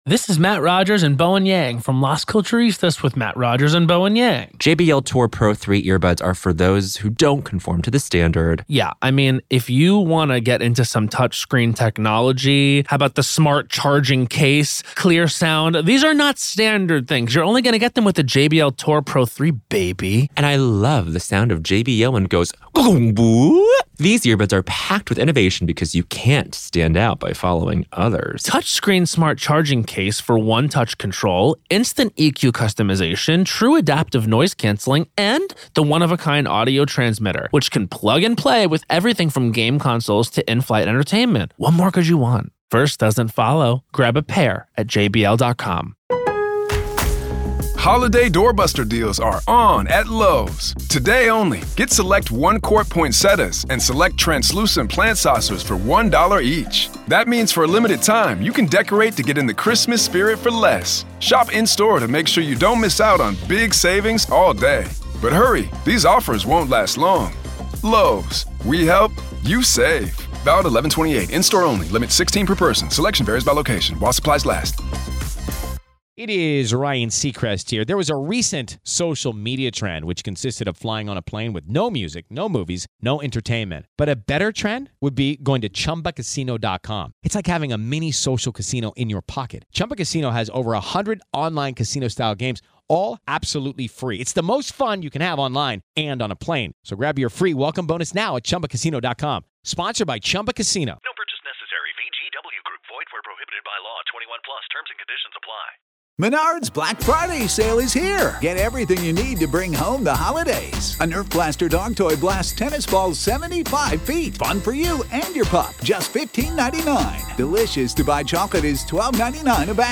With a mix of chills, sarcasm, and lived experience, they bring this unsettling story to life in the only way Real Ghost Stories Online can.